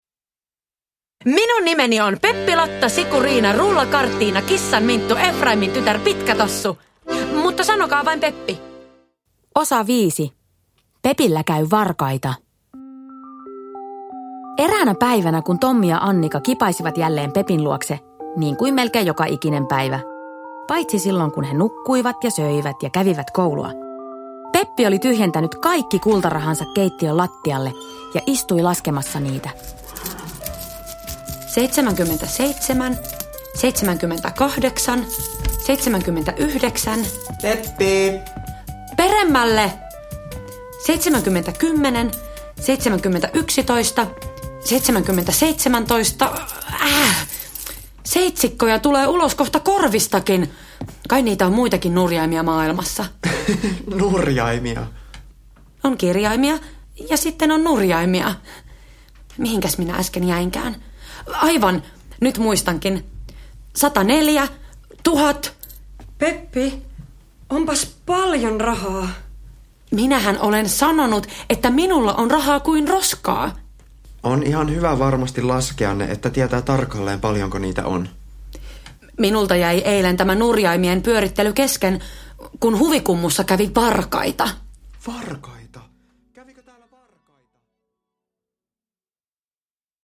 Kaikki on vinksin vonksin näissä iloisissa kuunnelmissa!
Kaikille tuttu Peppi Pitkätossu ilahduttaa nyt eläväisinä ja hauskoina kuunnelmina.